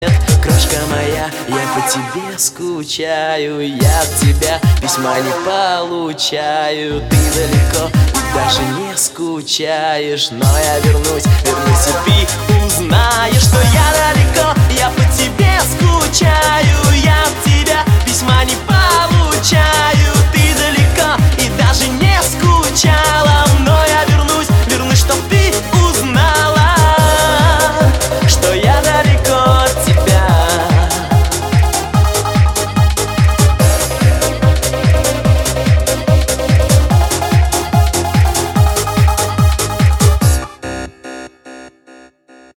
Главная » рингтоны на телефон » поп